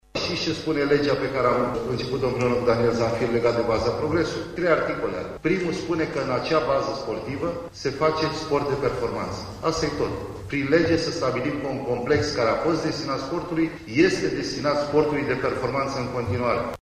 Campionii sportului au fost prezenţi, astăzi, la Senat, la invitaţia senatorilor Şerban Nicolae şi Daniel Zamfir care au iniţiat un proiect legislativ prin care baza sportivă Arenele BNR să revină în proprietatea statului.